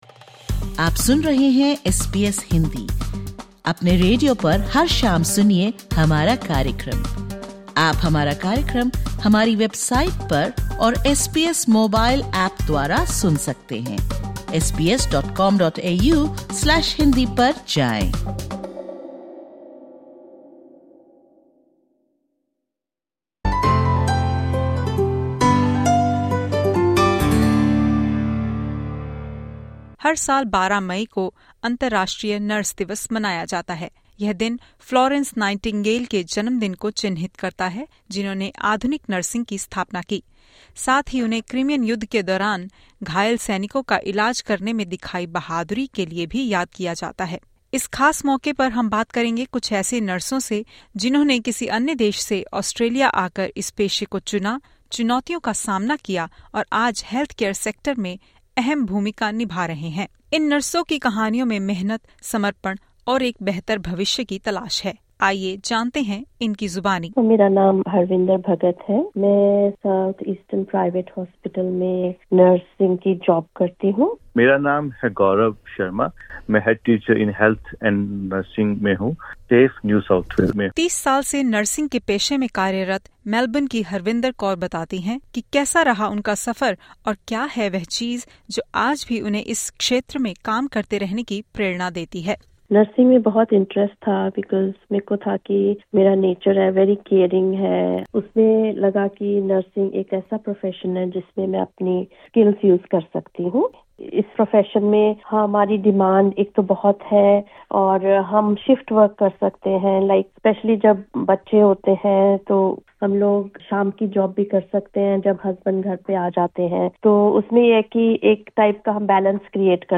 It’s a day to recognise the heart, dedication and strength that nurses bring to their work every day. To mark the occasion, we hear from nurses in our community as they share what it's really like to work in the profession here in Australia and the challenges they face.